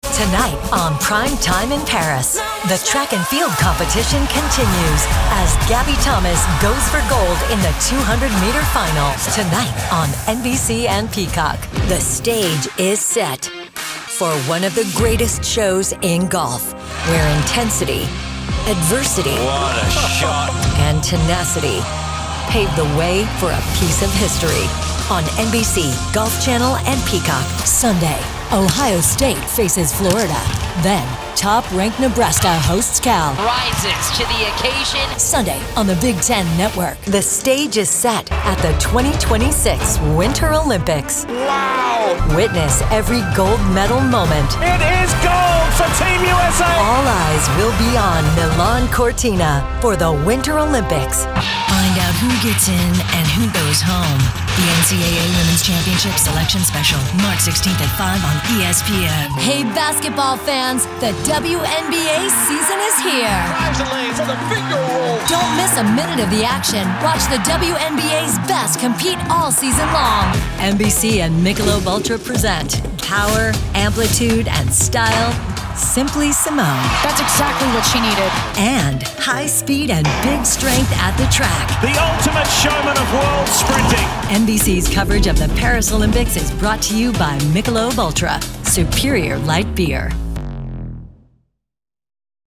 Sports Demo